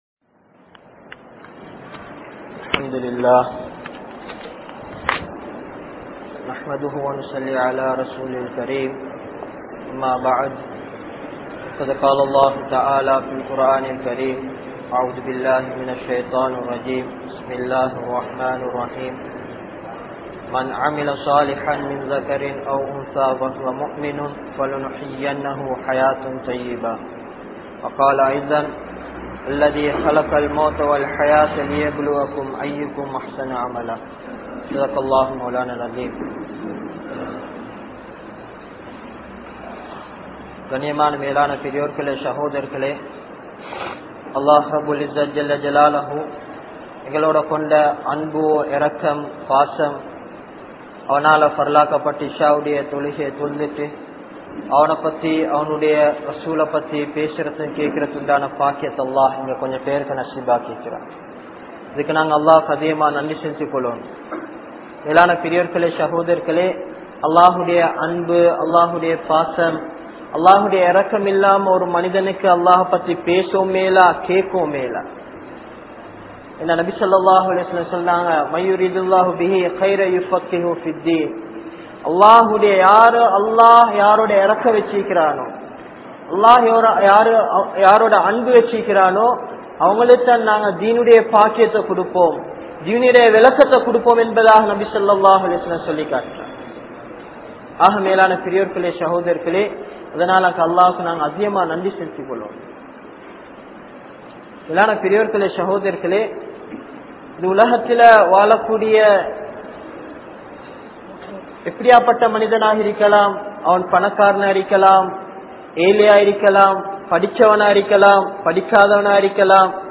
Nimmathiyaana Vaalkai Veanduma? (நிம்மதியான வாழ்க்கை வேண்டுமா?) | Audio Bayans | All Ceylon Muslim Youth Community | Addalaichenai